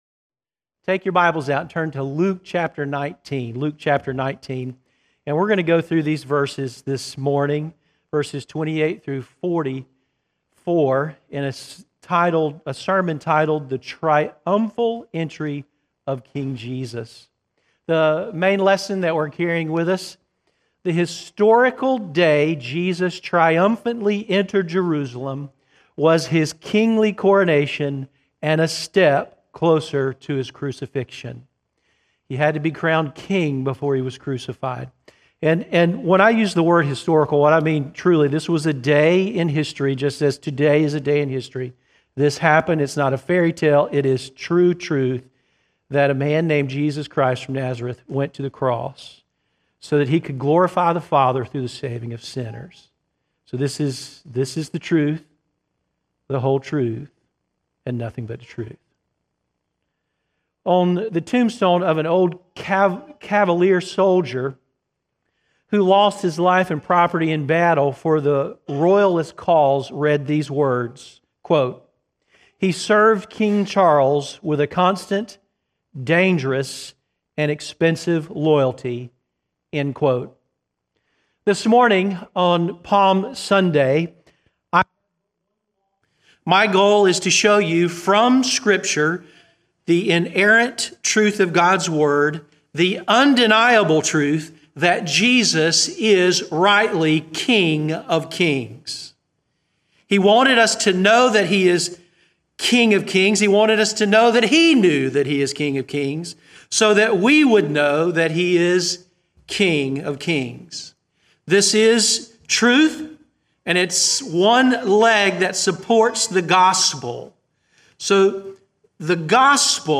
Service Audio